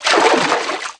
High Quality Footsteps
STEPS Water, Stride 02.wav